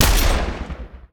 Sci-Fi Effects
weapon_sniper_003.wav